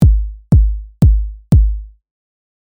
DR Kick Basic
シンセでキックを作るのは難しいと思っていたのですが、私が甘かったようです。
drkickbasic.mp3